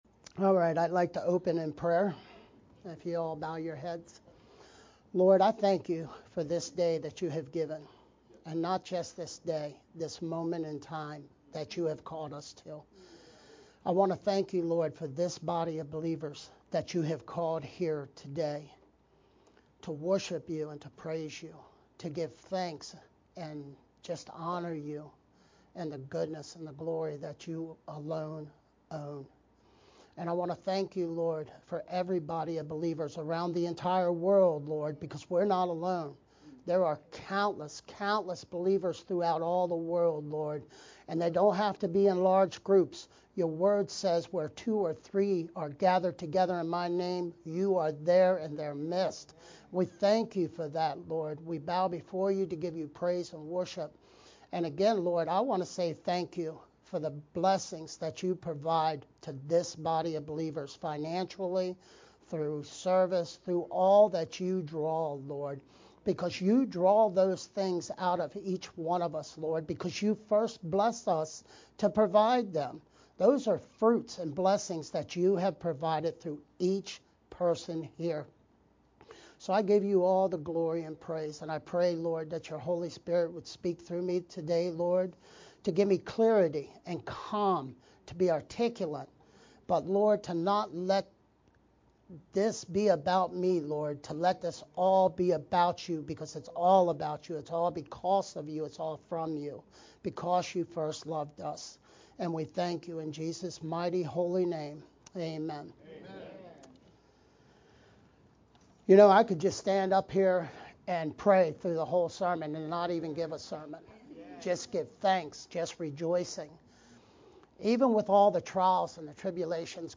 Oct-6th-VBCC-edited-sermon-only_Converted-CD.mp3